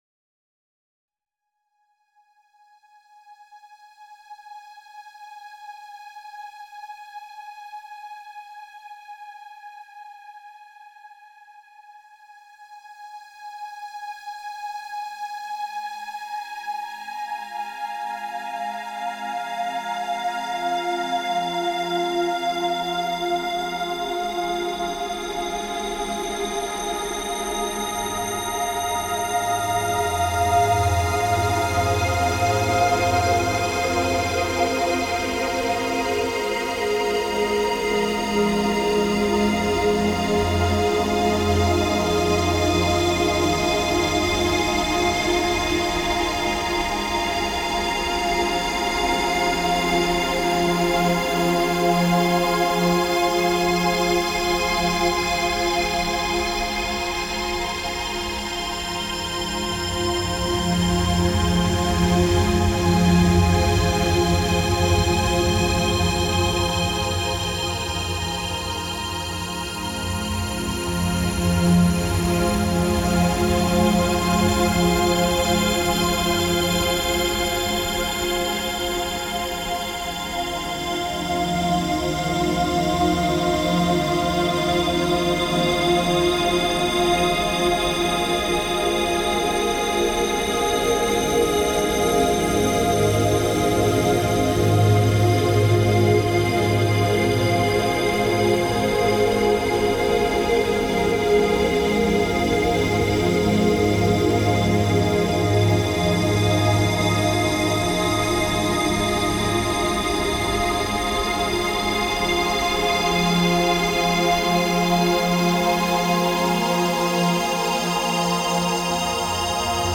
ocean-spray-meditations-189347.mp3